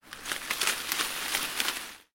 Звуки кустов
Шуршание раскачиваемых ветром кустов